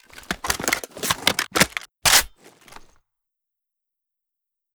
ru556_reloadempty_drum.ogg